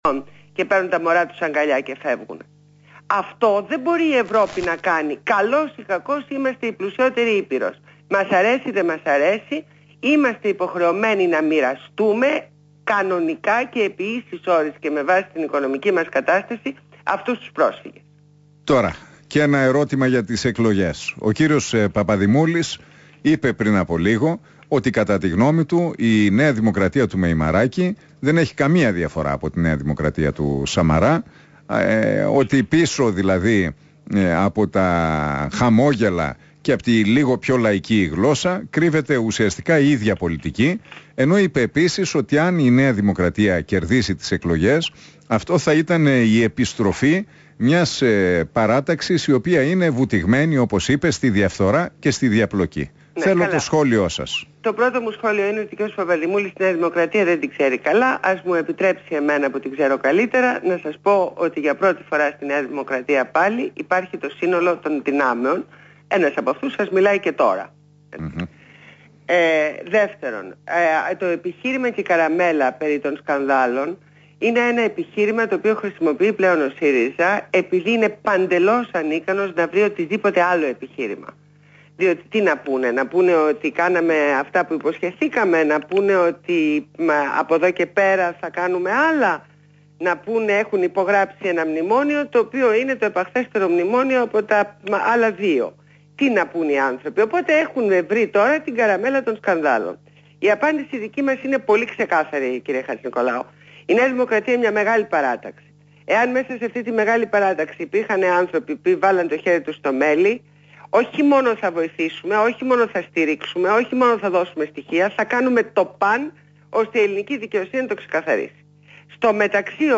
Η βουλευτής της ΝΔ Ντόρα Μπακογιάννη, παραχώρησε συνέντευξη στο ραδιοφωνικό σταθμό REAL FM 97,8 και το δημοσιογράφο Νίκο Χατζηνικολάου.